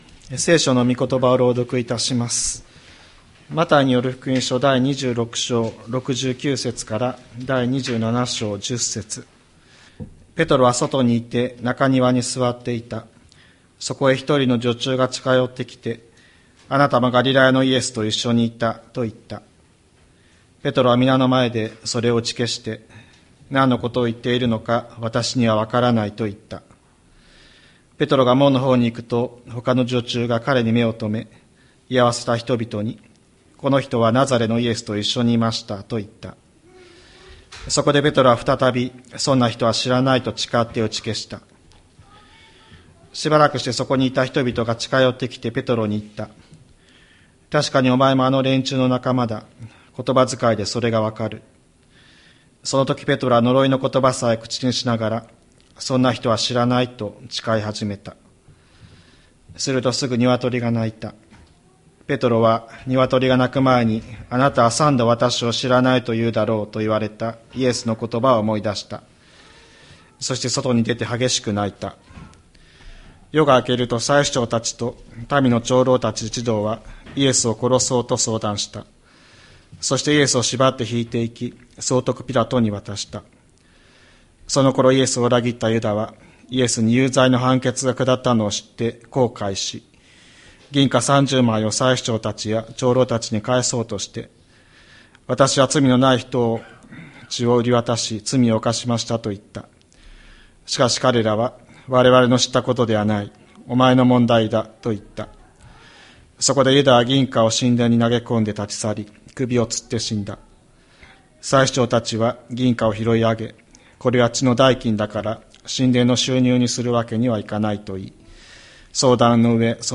2025年03月30日朝の礼拝「後悔と悔い改め」吹田市千里山のキリスト教会
千里山教会 2025年03月30日の礼拝メッセージ。